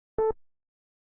countdown-8e2cc4d2.mp3